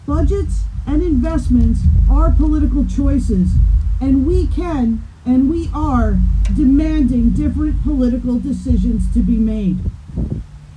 Hundreds of OPSEU members from the Quinte area’s post secondary institution, St Lawrence College in Kingston, and Algonquin College in Ottawa were also on hand.